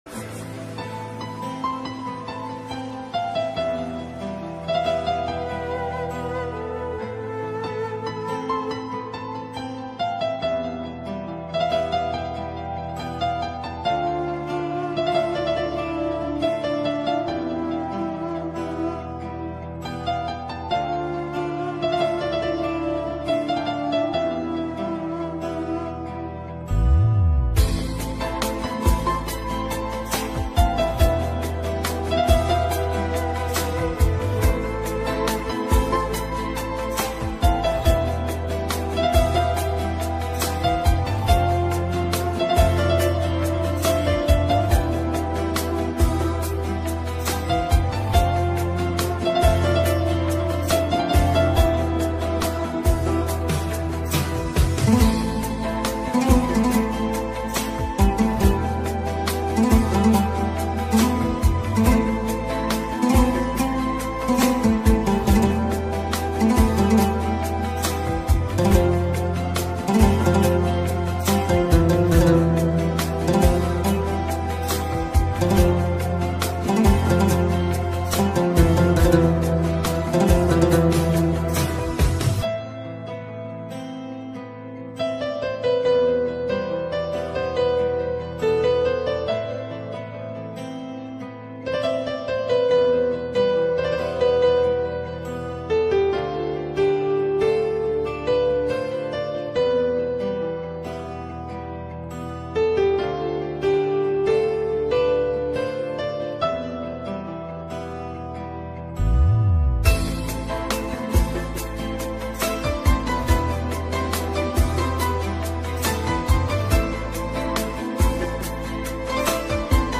.:!:. Fon Müziği .:!:.